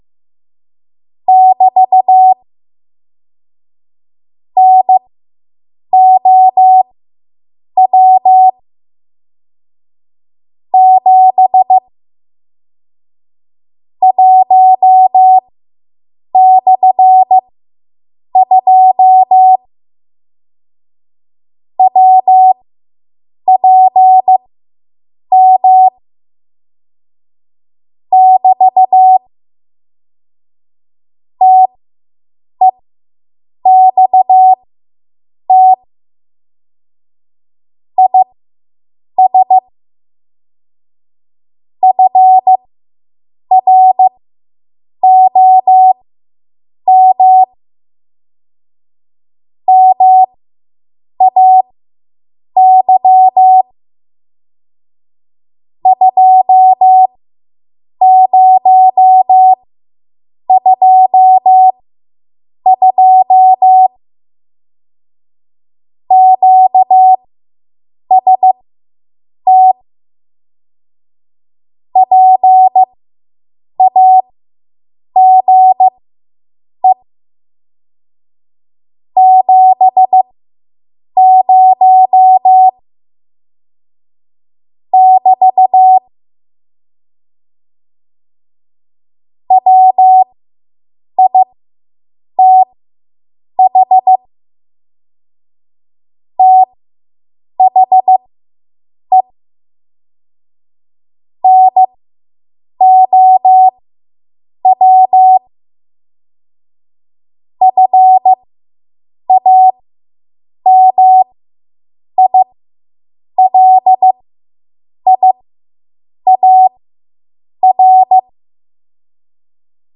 7.5 WPM Code Practice Archive Files
Listed here are archived 7.5 WPM W1AW code practice transmissions for the dates and speeds indicated.
You will hear these characters as regular Morse code prosigns or abbreviations.